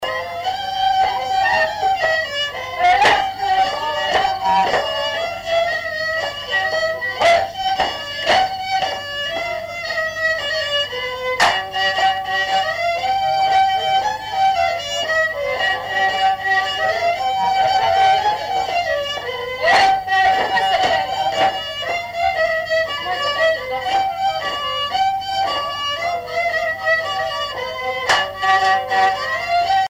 danse : rondeau
Répertoire d'un bal folk par de jeunes musiciens locaux
Pièce musicale inédite